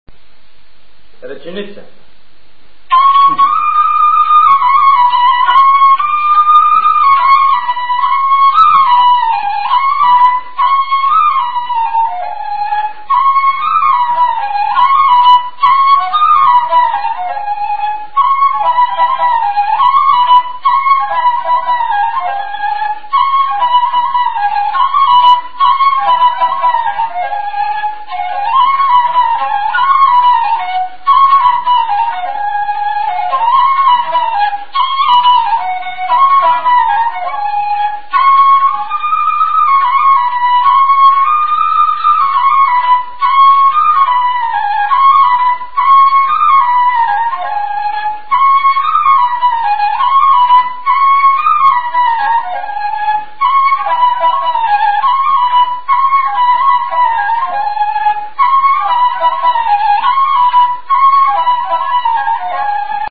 музикална класификация Инструментал
тематика Хороводна (инструментал)
размер Седем шестнадесети
фактура Едногласна
начин на изпълнение Солово изпълнение на кавал
фолклорна област Средна Северна България
начин на записване Магнетофонна лента